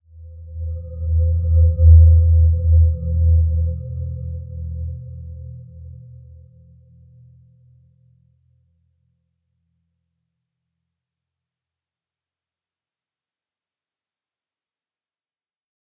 Dreamy-Fifths-E2-p.wav